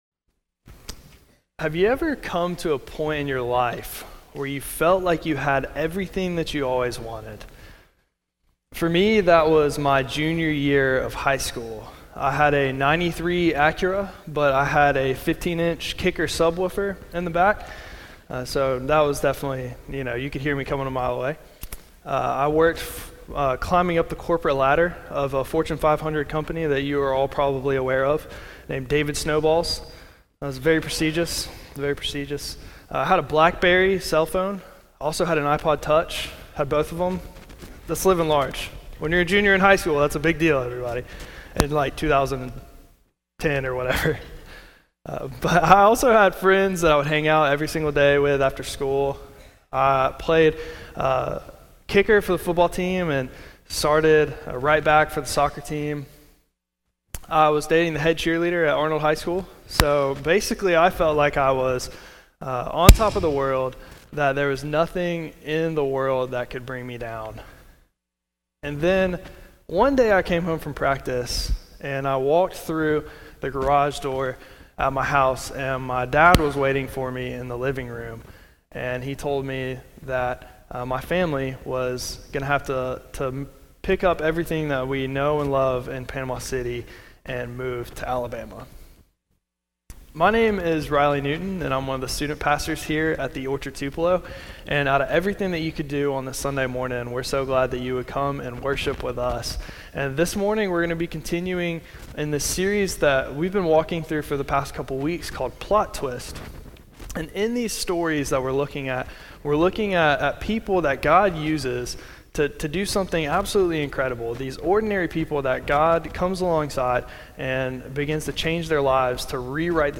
Sermon Series: Plot Twist